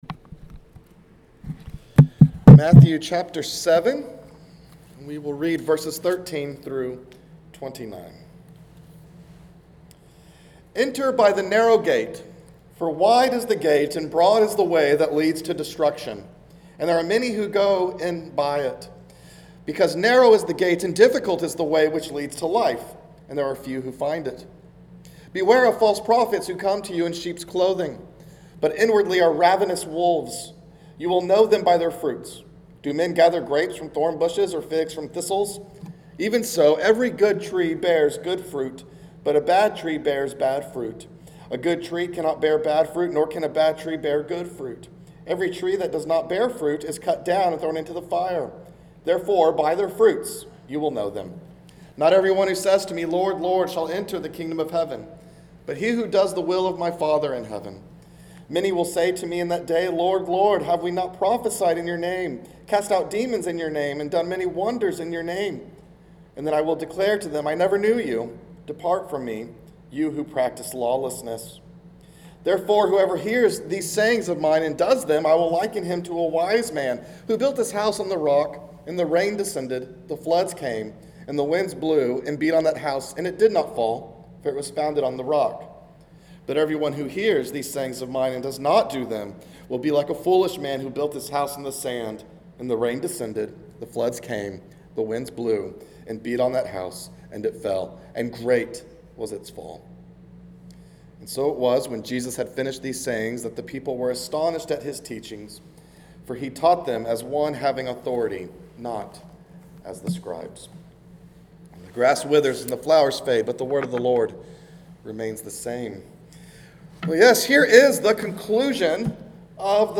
Afternoon Service